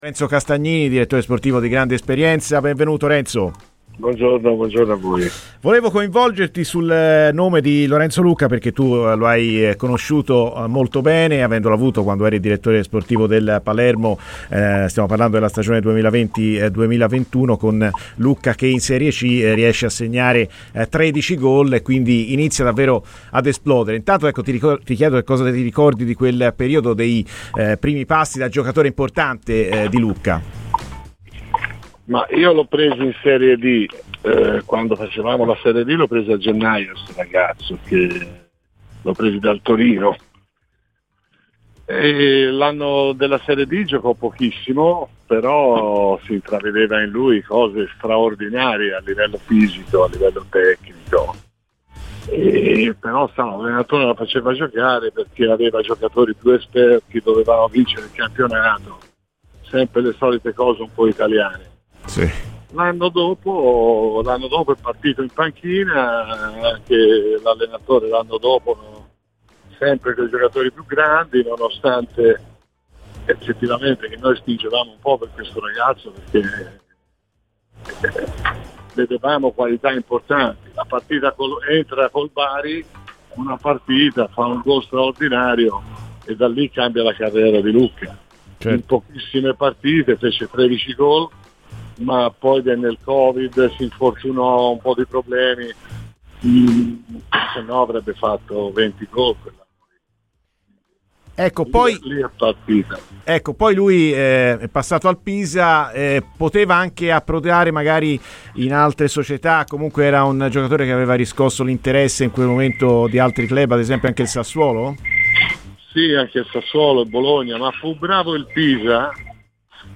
Lorenzo Lucca lo ha avuto al Palermo, cosa si ricorda di quel periodo?